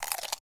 Crunch Bite Item (5).wav